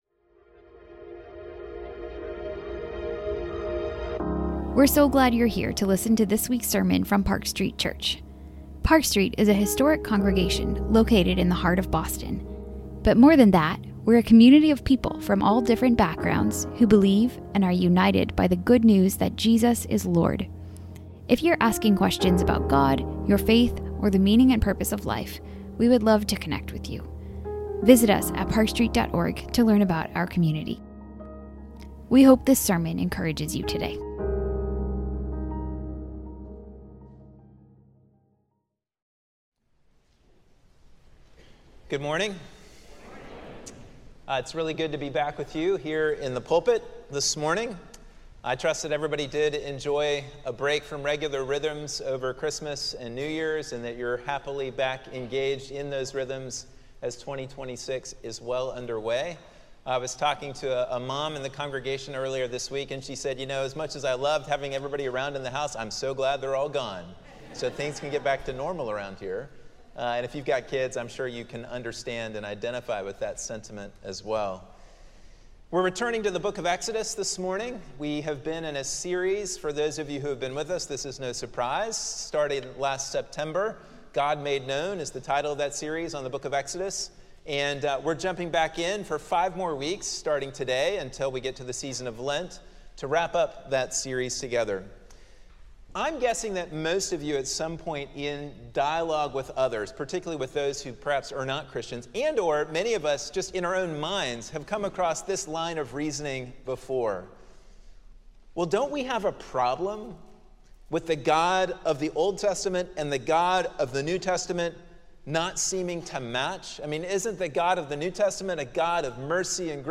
Sermons | Park Street Church